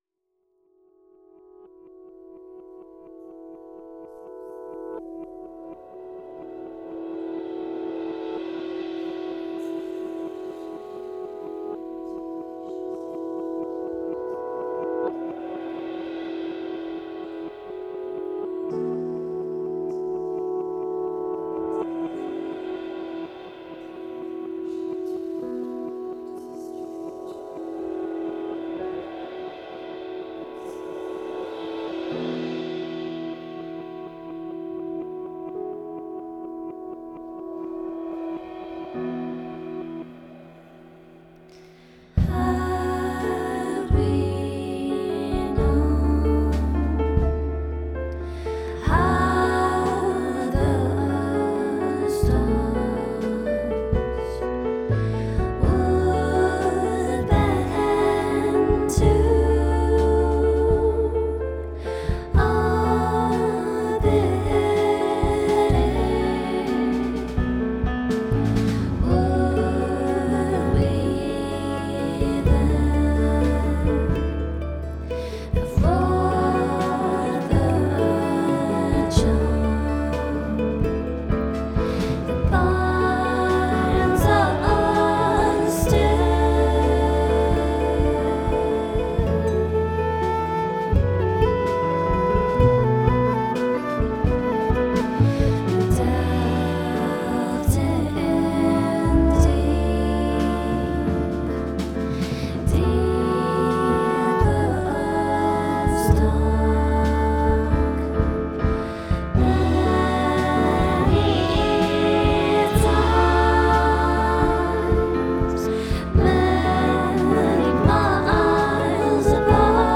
soprano saxophone
vocals, piano, guitars, tin whistle, bodhran
mellotron
double bass
flute
mandolin
fiddle
percussion, drumkit. Tracks 1- 3 recorded and engineered